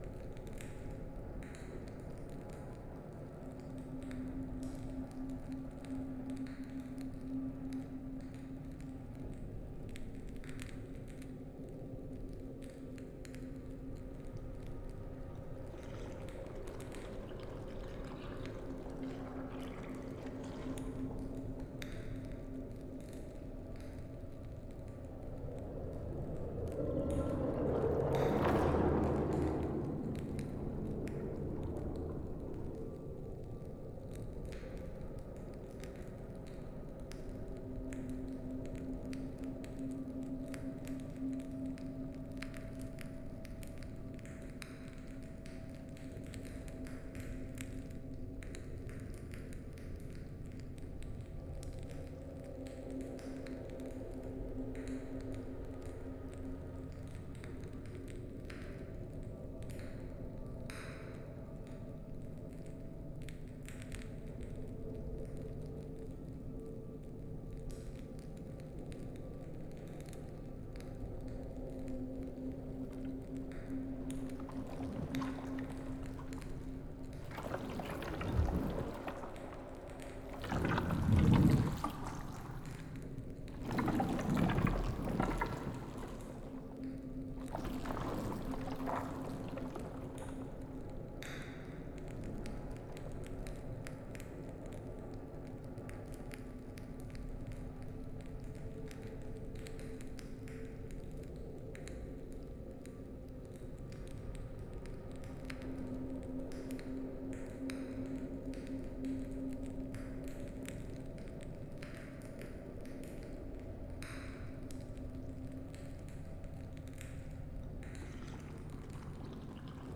muddy-torchlit-ooze-dungeon.ogg